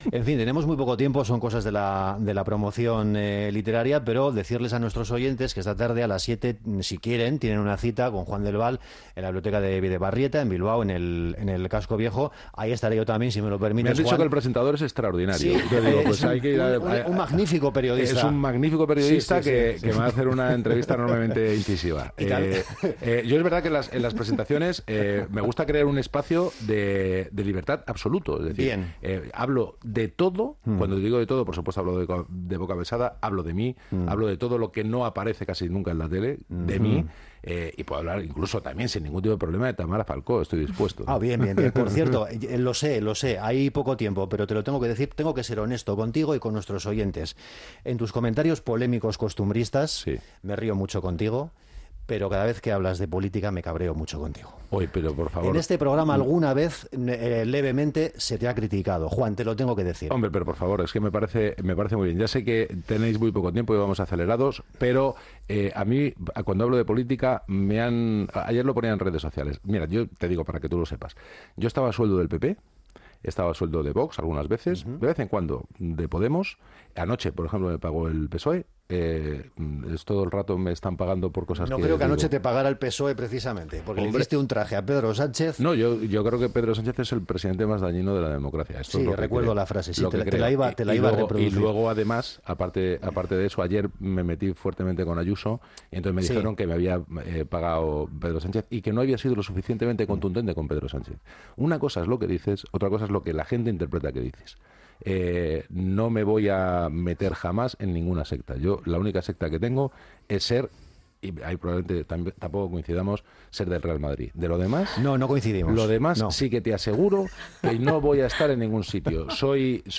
La conversación terminaba con una sonrisa y un apretón de manos.